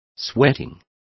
Complete with pronunciation of the translation of sweating.